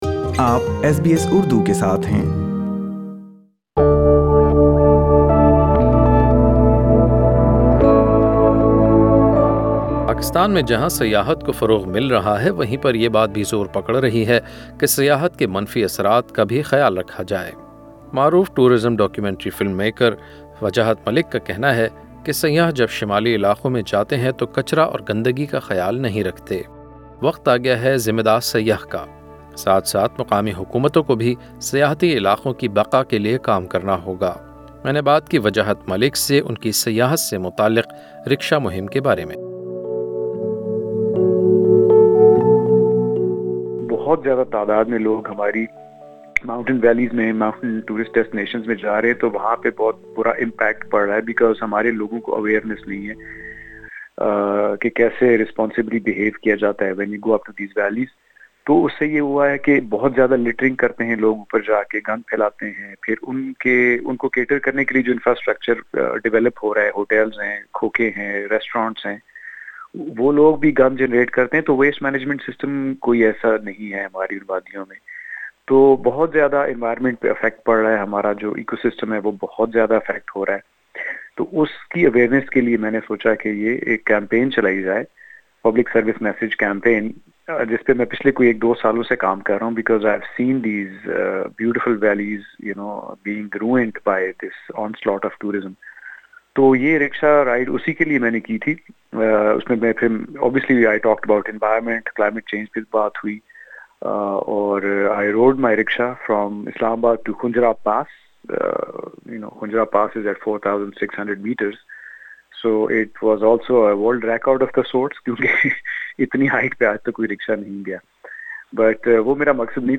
ایس بی ایس اردو سے گفتگو